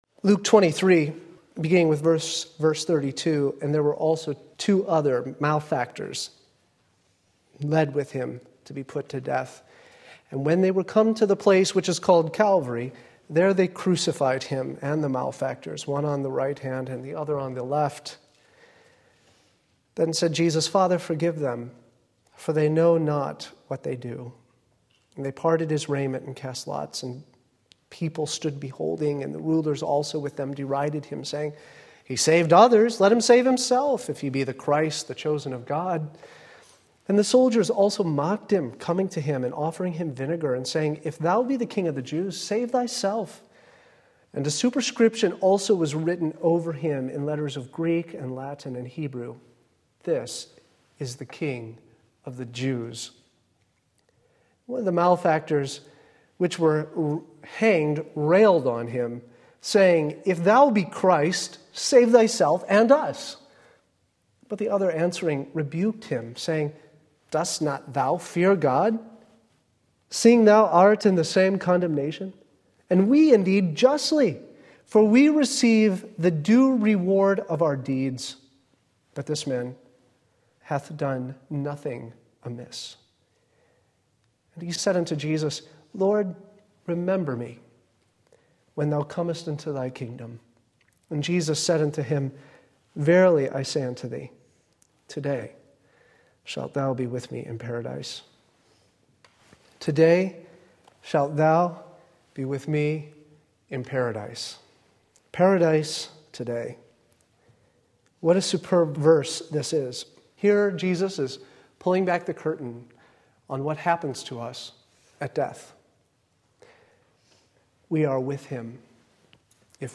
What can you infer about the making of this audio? Paradise Today Luke 23:39-43 Sunday Afternoon Service